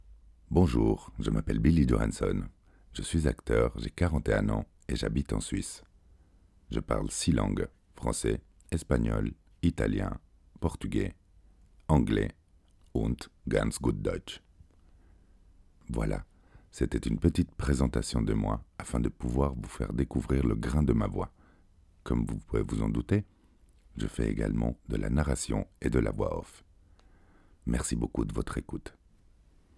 Voix off
40 - 80 ans - Baryton-basse